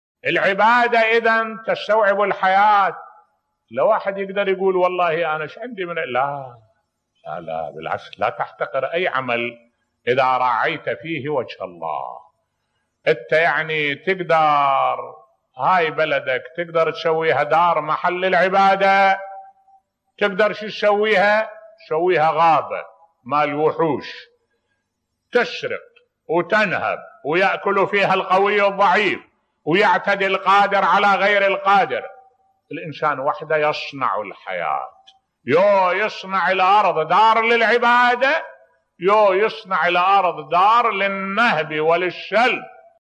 ملف صوتی الإنسان يصنع الحياة بصوت الشيخ الدكتور أحمد الوائلي